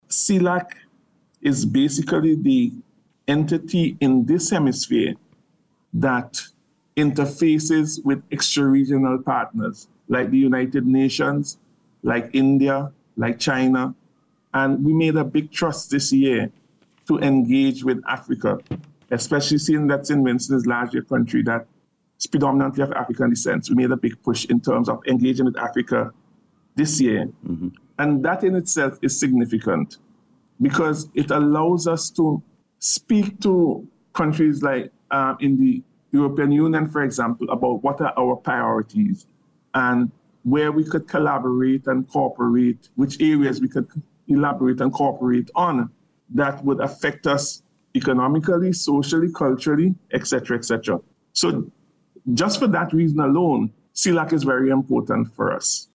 That’s the view of this country’s Ambassador to Cuba, Ellsworth John, who was speaking about the summit on NBC’s Face to Face Program this morning.